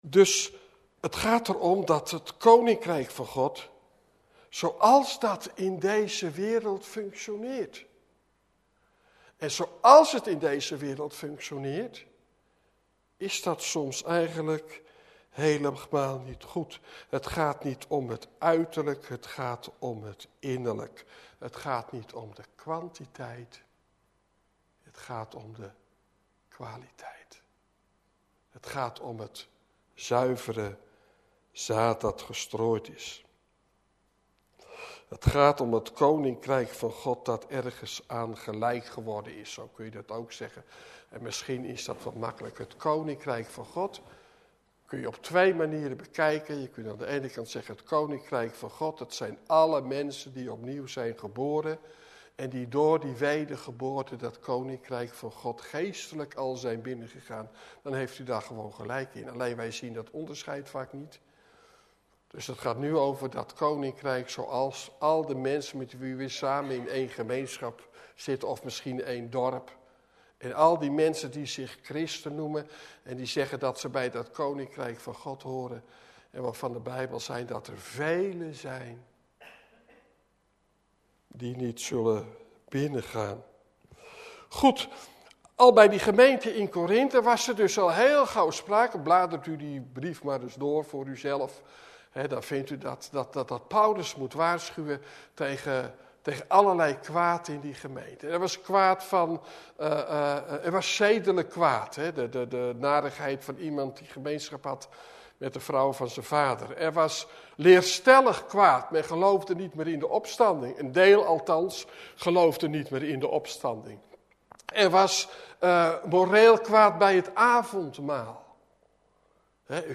Door een technische storing is het eerste deel van de preek weggevallen.